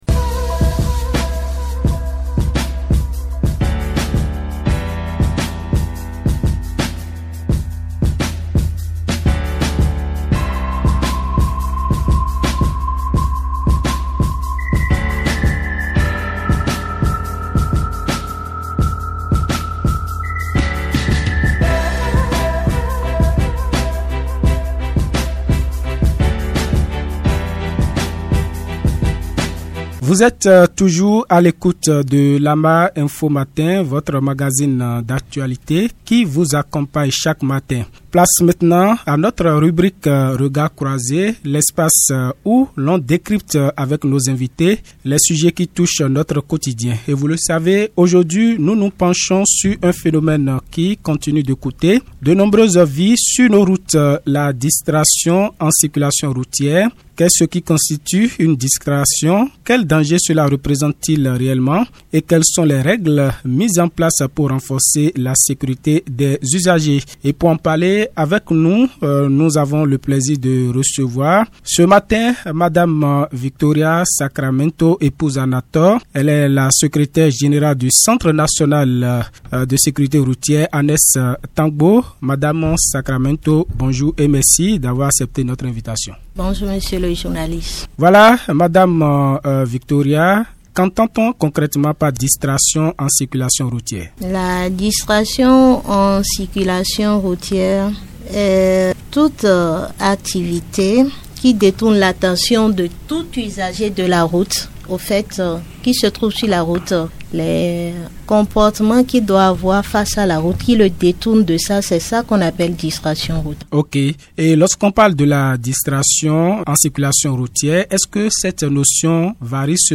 DEBAT LAMA MATIN INFO DE CE JEUDI 27 NOVEMBRE 2025